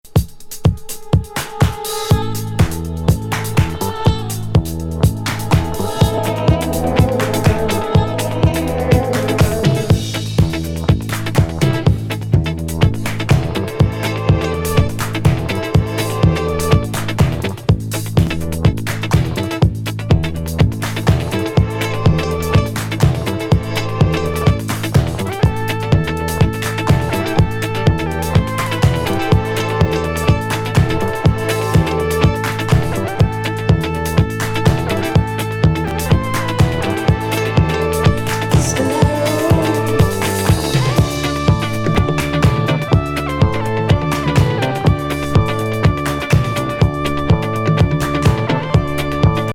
カナディアン・シンセ・ディスコ・グループ79年作。